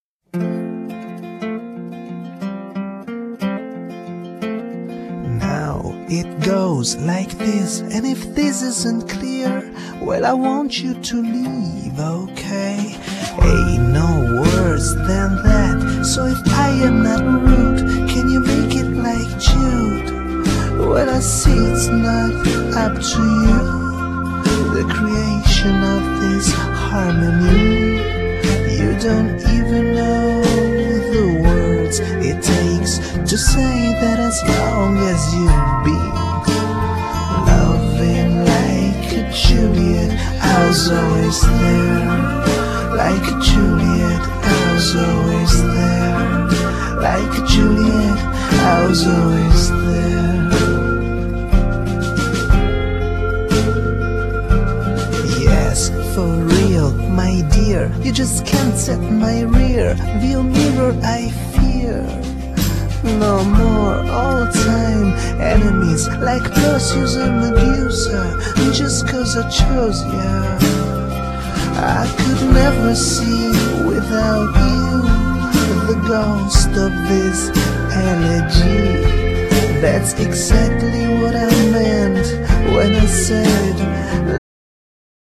Genere : Pop
La romantica ballata